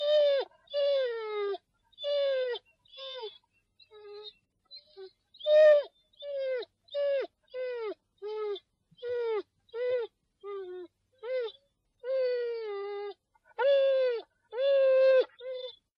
Звуки скулящей собаки
Скулеж собаки